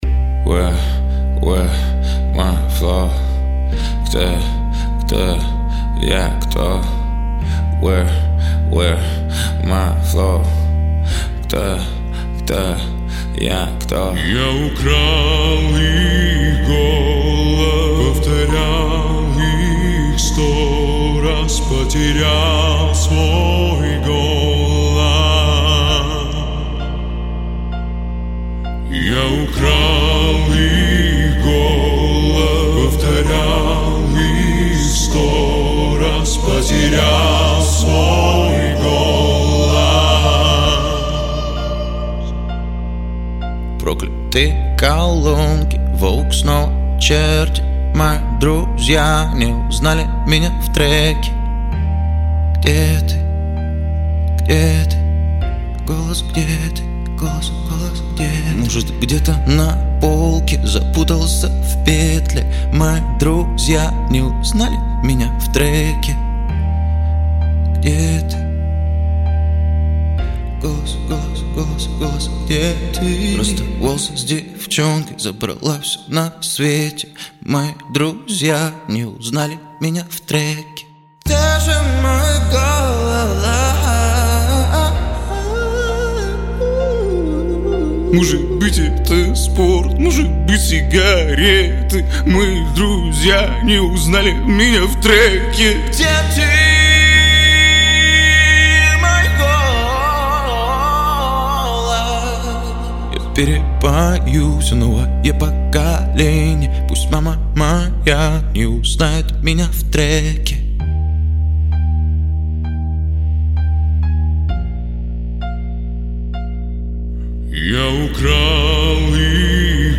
Хип-хоп
Жанр: Хип-хоп / Русский рэп